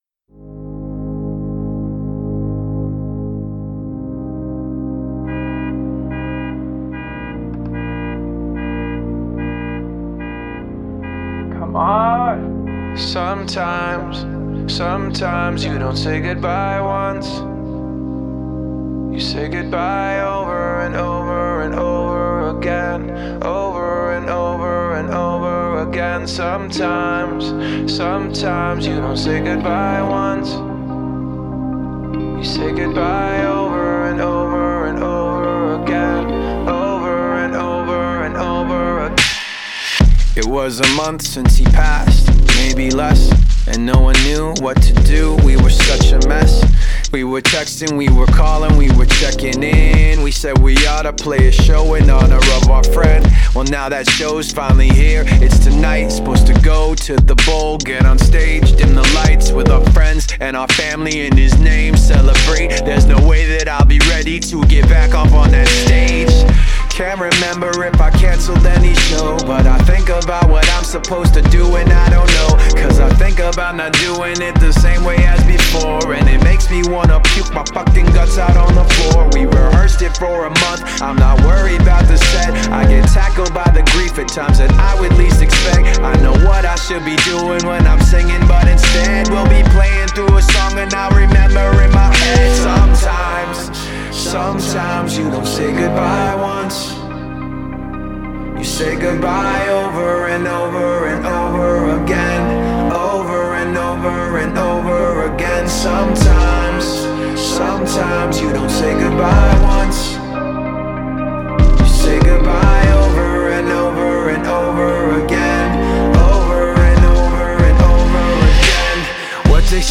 موسیقی رپ RAP METAL Alternative